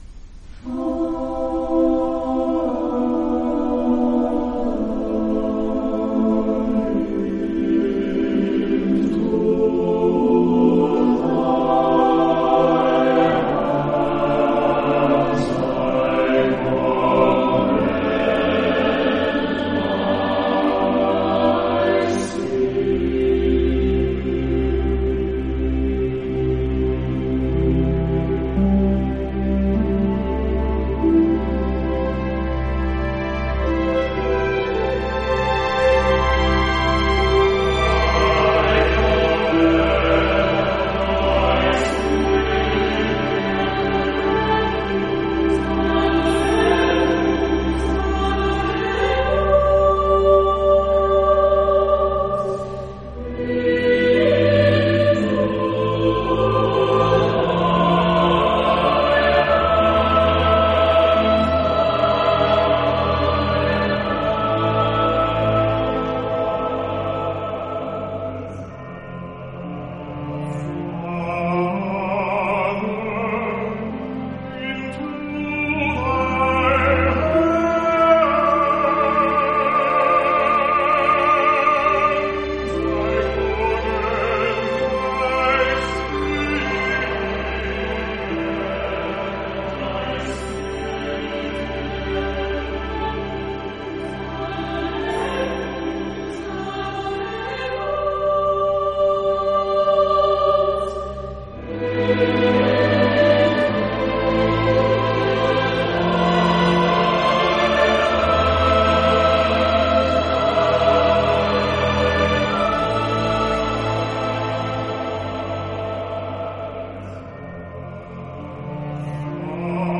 soloist